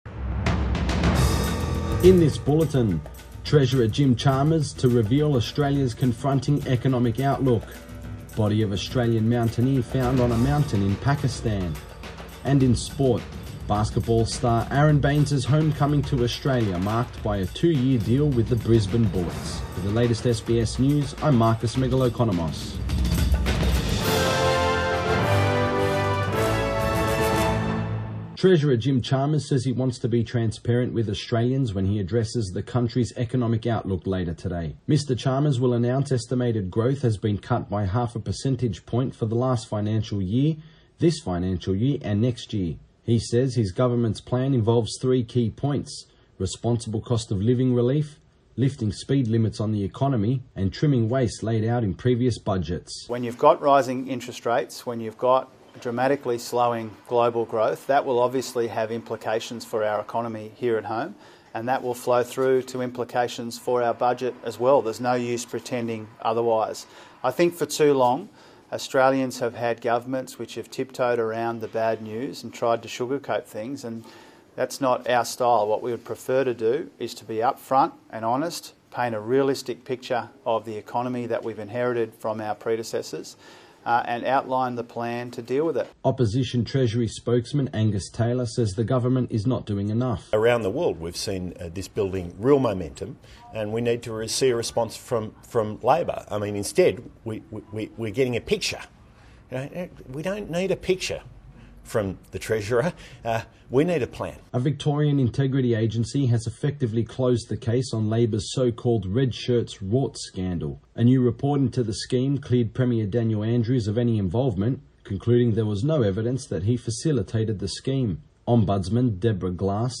Midday bulletin 28 July 2022